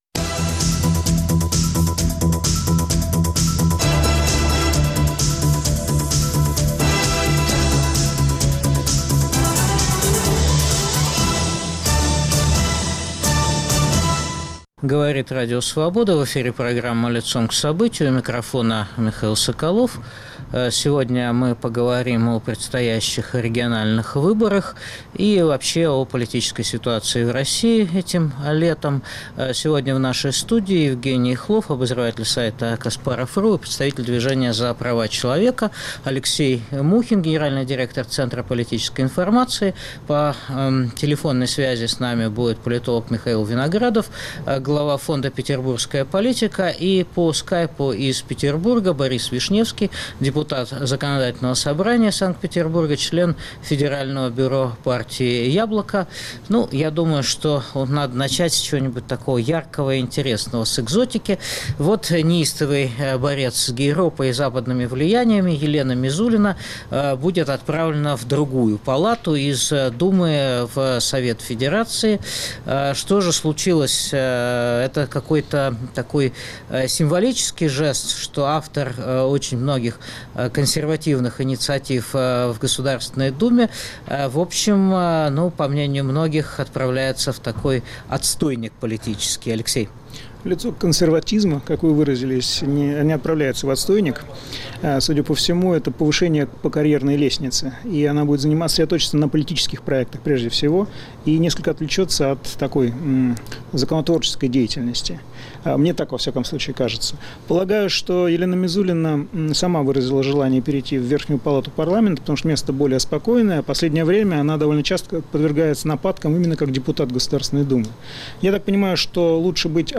Дискутируют политологи